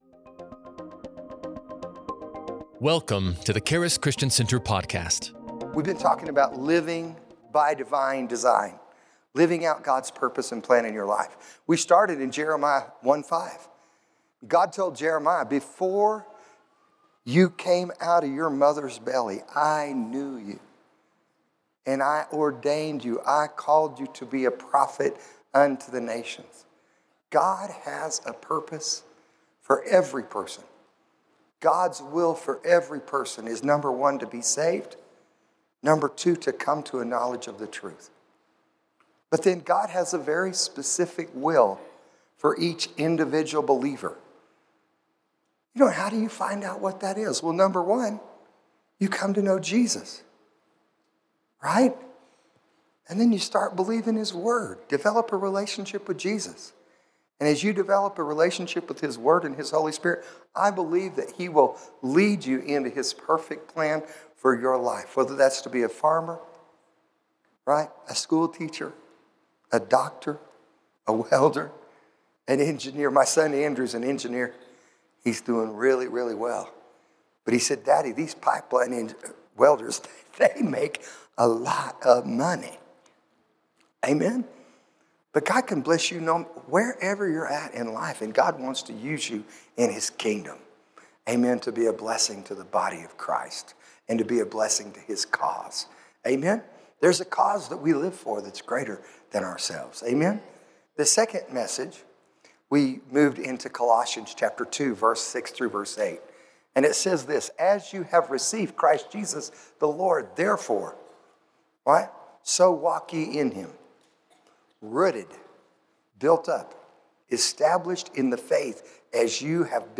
This sermon encourages you to grow closer to Jesus, study the Bible, and let the Holy Spirit guide your spirit, soul, and body to victory. https